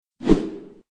Download Wind sound effect for free.
Wind